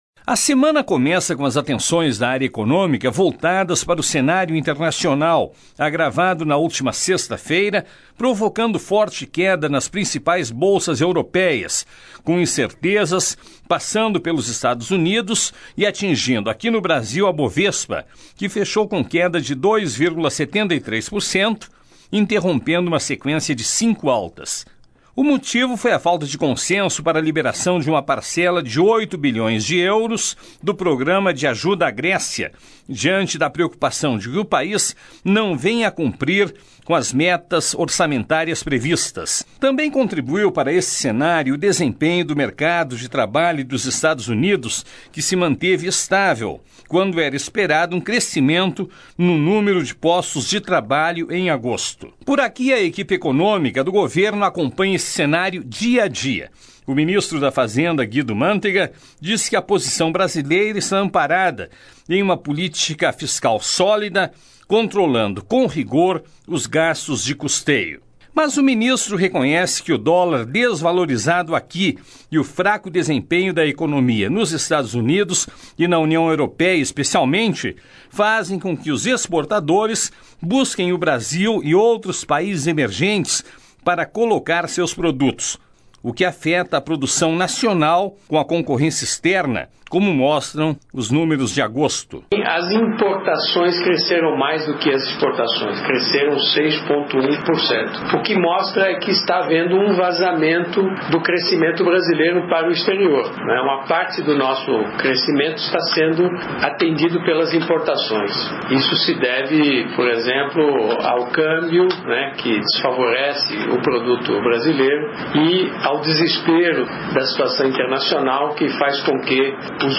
O FMI e a União Européia voltam a negociar com a Grécia a liberação de uma percela de 8 bilhões de euros do programa de ajuda à economia grega. O governo brasileiro acompanha os problemas na economia da Europa e dos Estados Unidos, preocupado também com o mercado interno. O assunto na reportagem